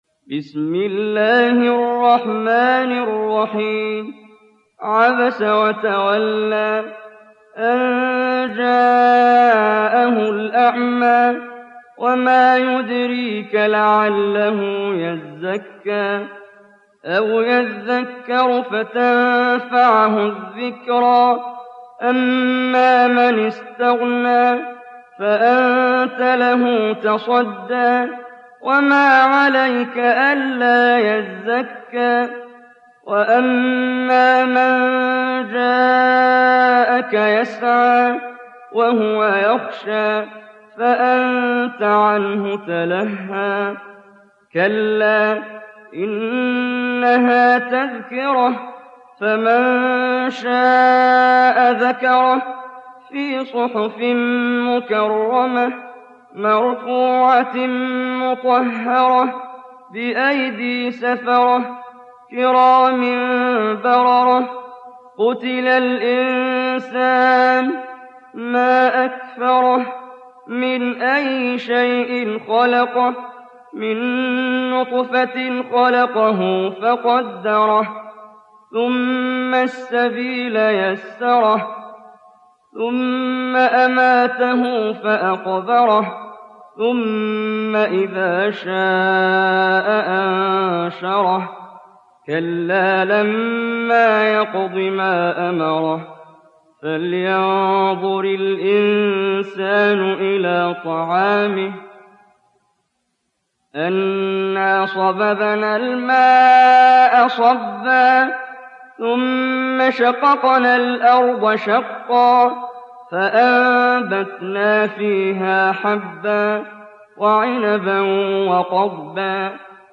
دانلود سوره عبس mp3 محمد جبريل روایت حفص از عاصم, قرآن را دانلود کنید و گوش کن mp3 ، لینک مستقیم کامل